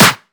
• Clap Single Shot F Key 16.wav
Royality free clap - kick tuned to the F note. Loudest frequency: 4112Hz
clap-single-shot-f-key-16-96p.wav